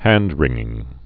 (hăndrĭngĭng)